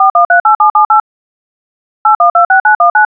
GC256J9-tones.wav